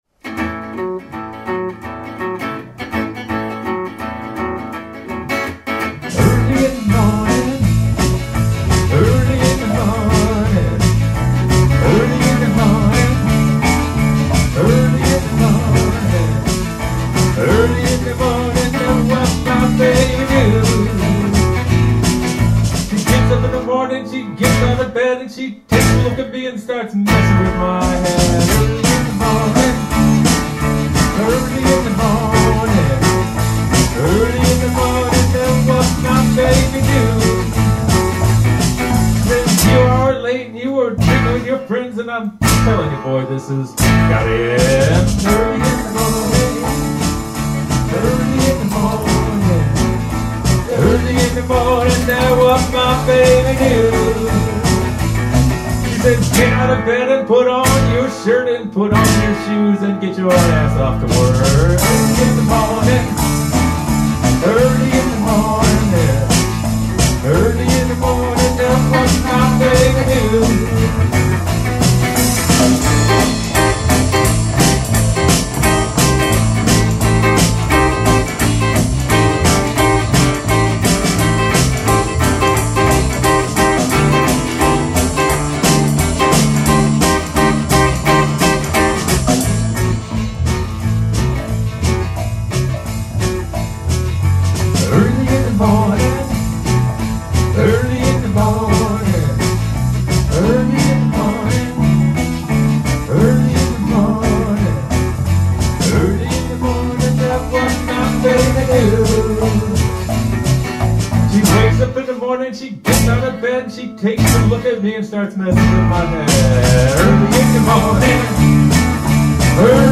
Acoustic and vocals
Strat
Bass
Percussion October 9